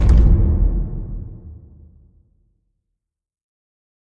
带有混响的空间爆炸
描述：强大的科幻空间爆炸带有许多免费混响。使用Audacity创建。但是你认为合适。
Tag: 空间 爆炸 低端